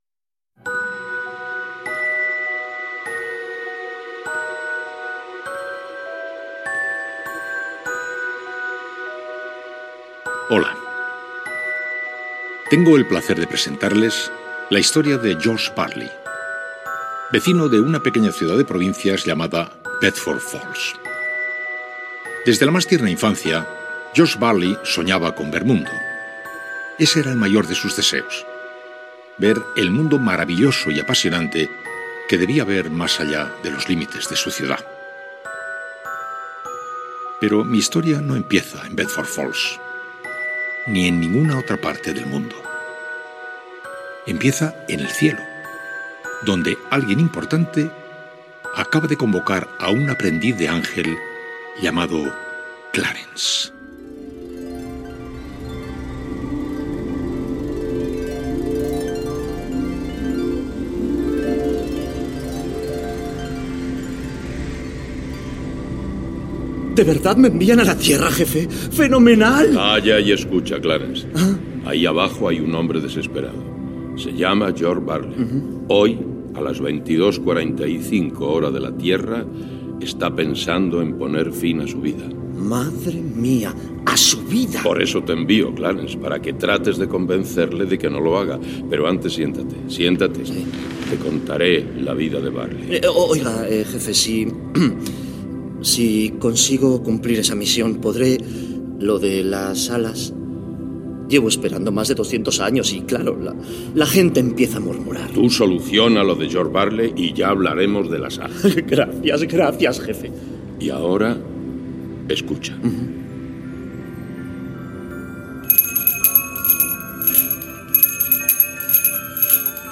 Fragment de l'adaptació radiofònica de l'obra "Qué bello es vivir". El narrador situa a l'acció i primeres escenes
Ficció